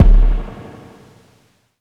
Live_kick_6.wav